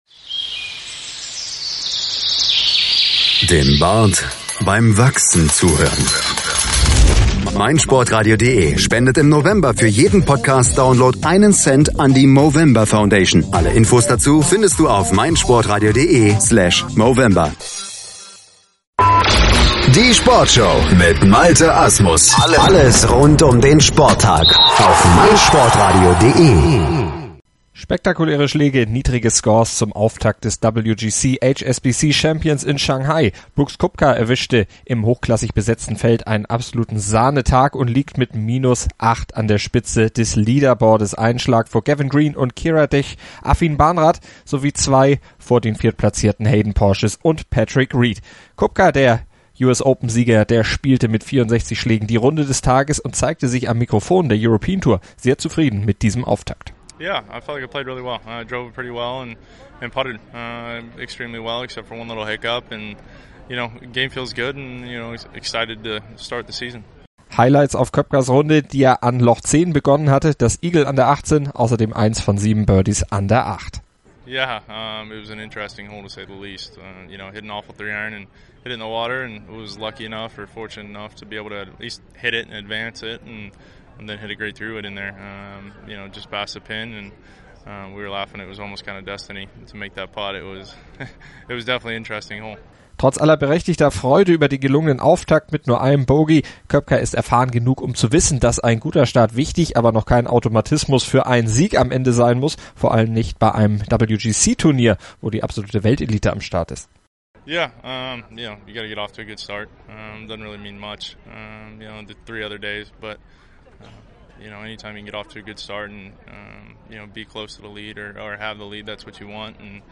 lässt die Spitzenreiter selbst zu Wort kommen.